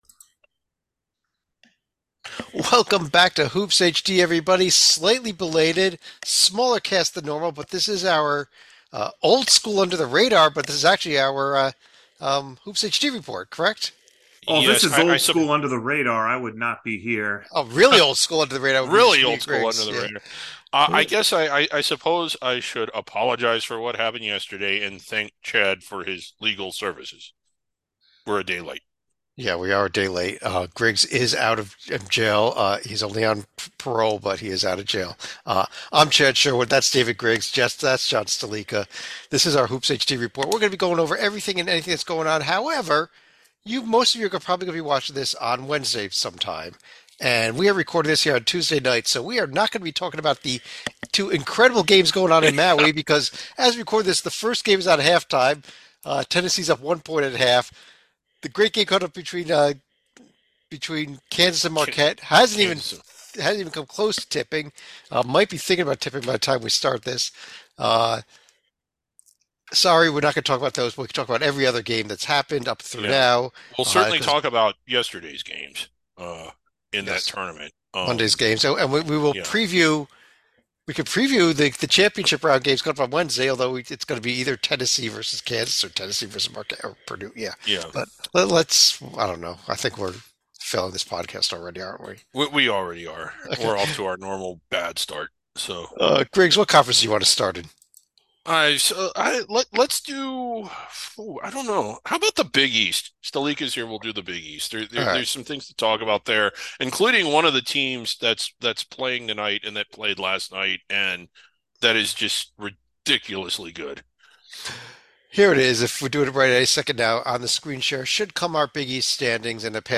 And for all your radio lovers, below is an audio only version of the show…